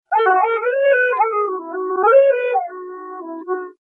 标签： 127 bpm Jazz Loops Flute Loops 648.86 KB wav Key : Unknown
声道立体声